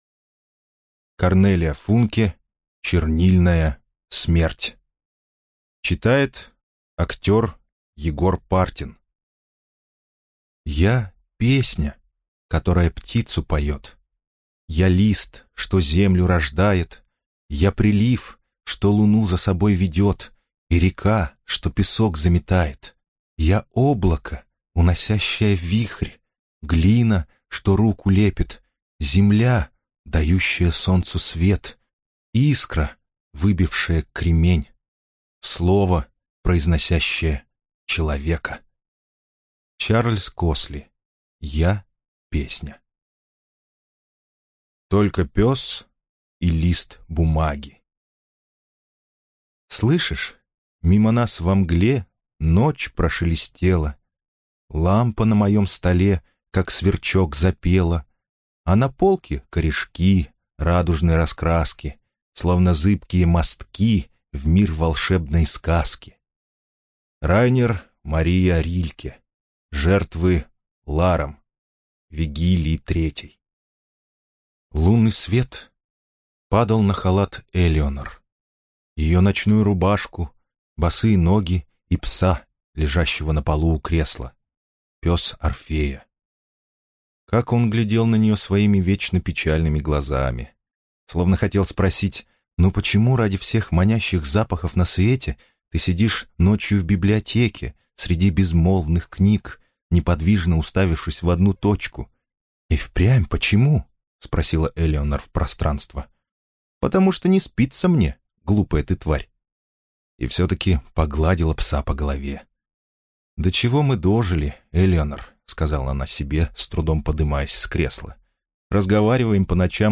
Аудиокнига Чернильная смерть | Библиотека аудиокниг